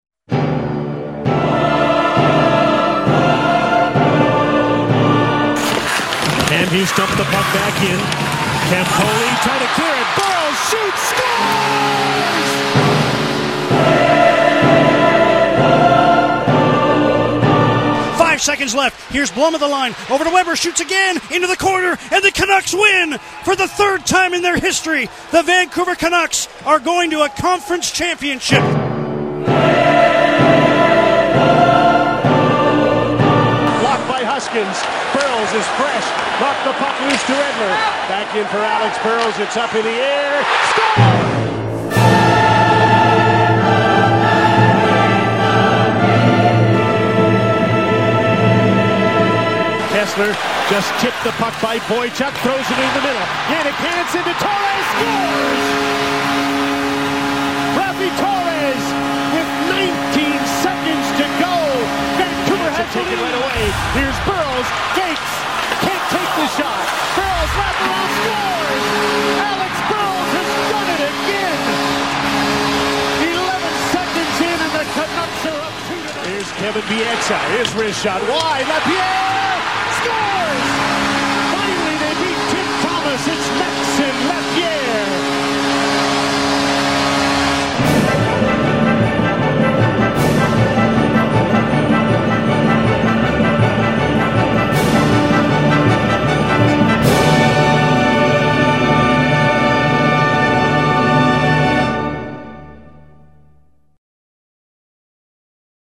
I created today’s audio-montage to celebrate all the series clinchers (Chicago, Nashville & San Jose) and game winners from the Boston Bruins series.  The song is called “O Fortuna” and you’ll recognize it as a classic.